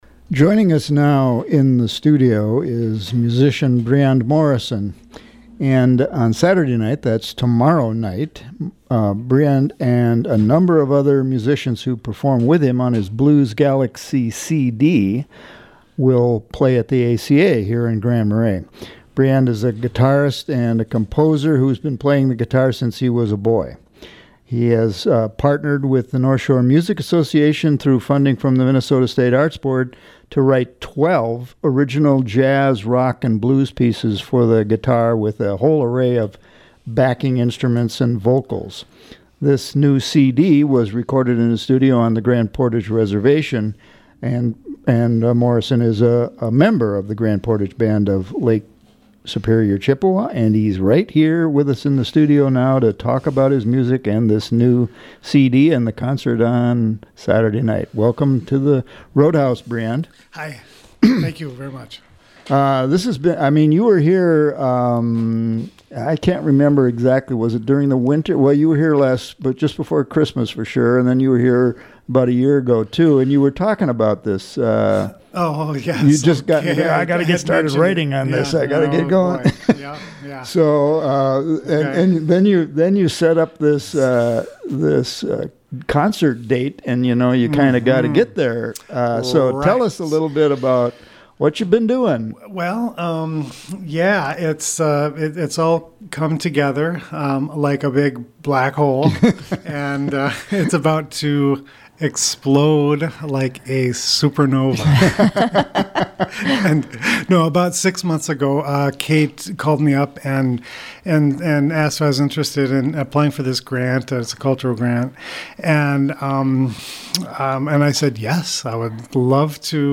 The CD includes performances of 17 local musicians, and several songs are sampled in this interview.